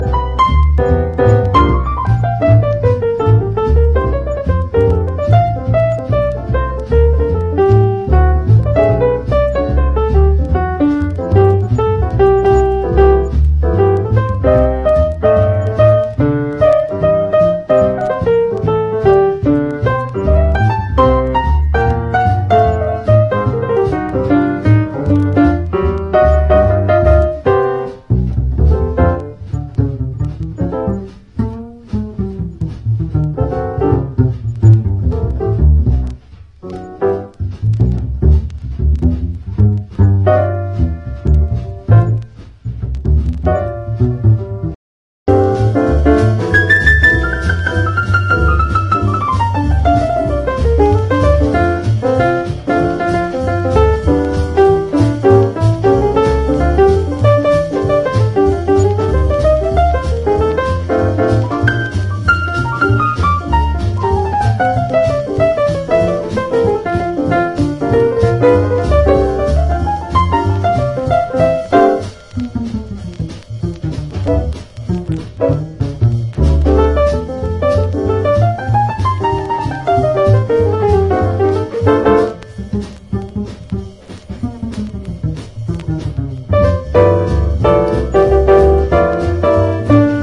JAZZ / MAIN STREAM / EURO JAZZ / MODAL
イタリア産モーダル・ジャズ、そのクールネスが凝縮したような逸品！